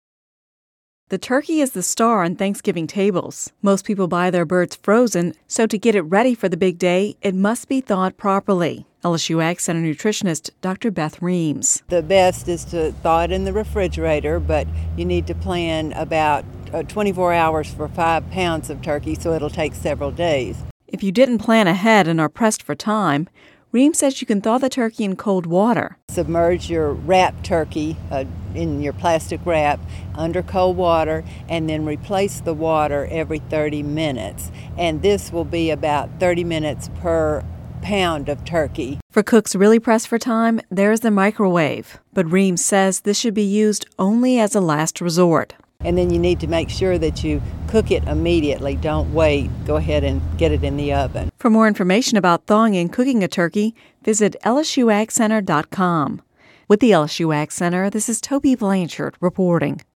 (Radio News 11/15/10) The turkey is the star on Thanksgiving tables.